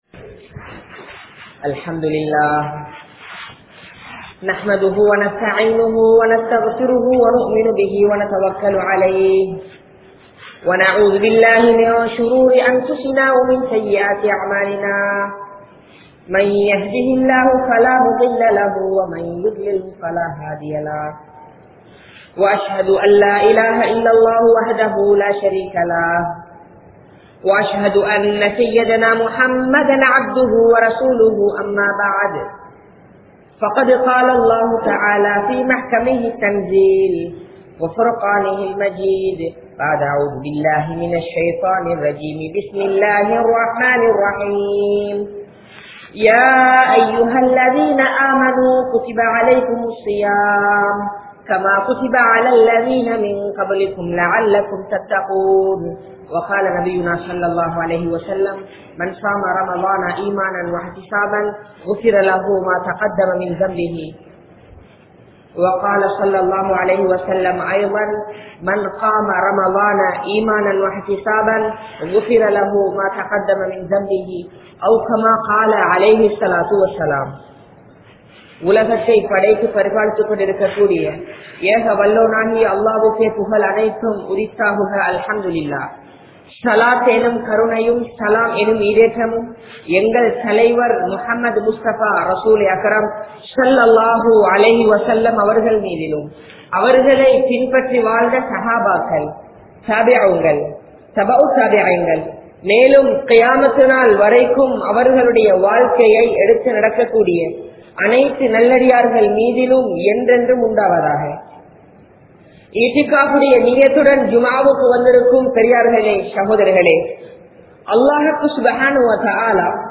Ramalaanin Noakkam (ரமழானின் நோக்கம்) | Audio Bayans | All Ceylon Muslim Youth Community | Addalaichenai